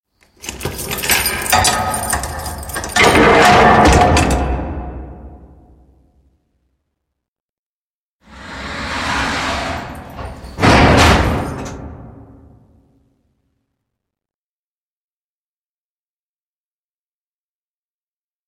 На этой странице собраны звуки тюрьмы — от хлопающих дверей камер до приглушенных разговоров в коридорах.
Скрип металлической двери в тюремной камере